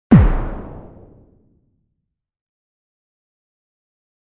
SE（爆発）
爆発。どーん。